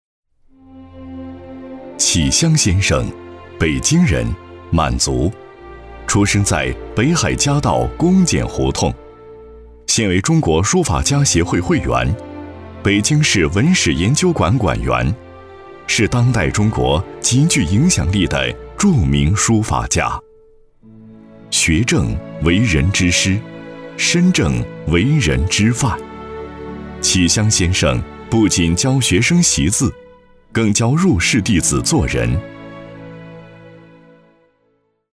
男国171_专题_人物_人物专题_浑厚.mp3